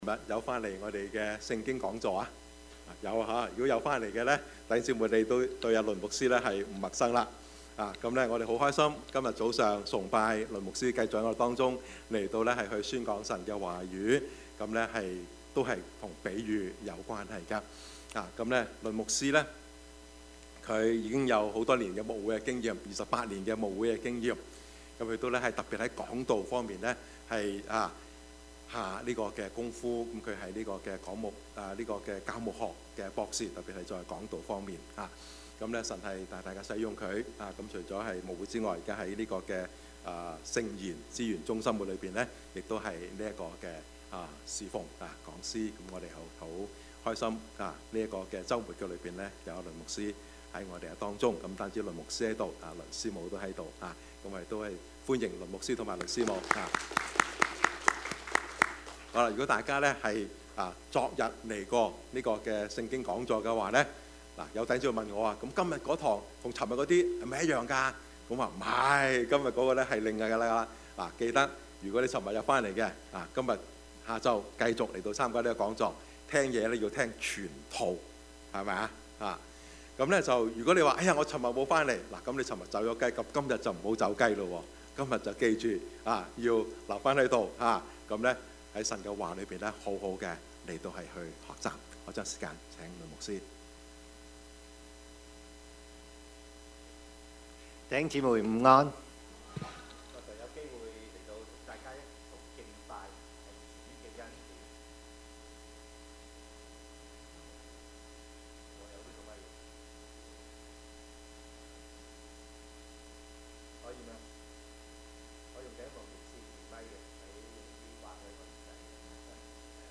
Service Type: 主日崇拜
） Topics: 主日證道 « 比喻的信息: 活在主的恩典中 但願這事普傳天下 »